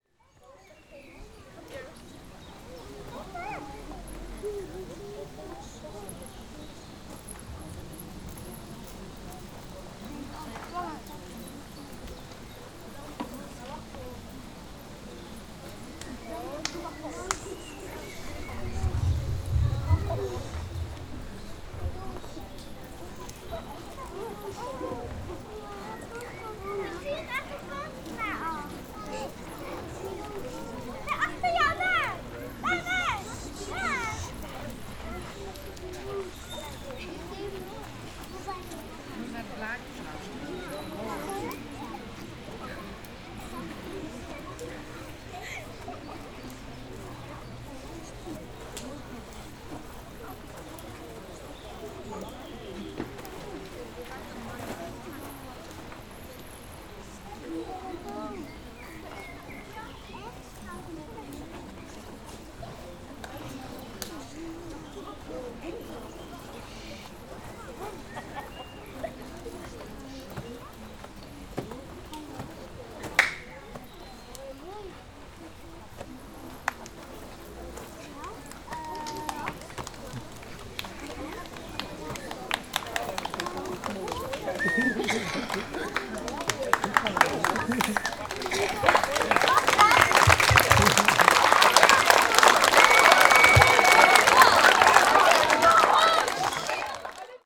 description all the sounds you hear right here belong to instant composition #016